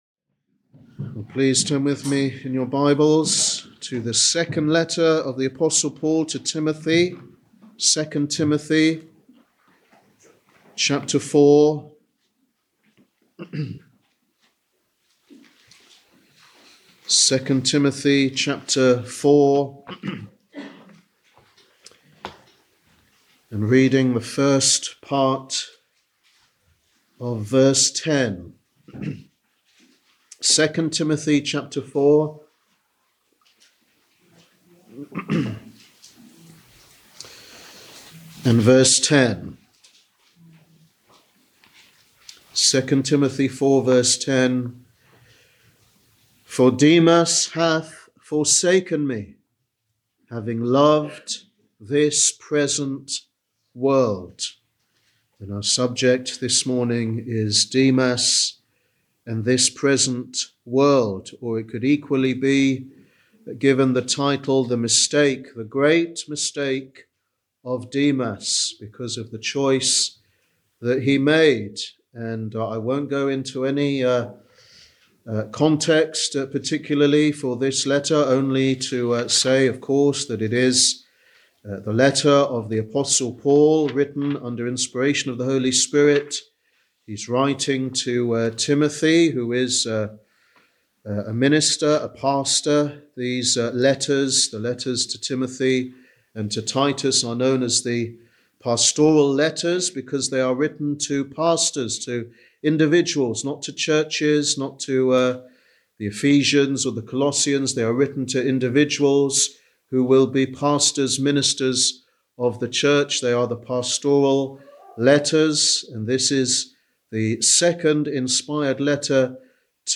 Sunday Evangelistic Service
Sermon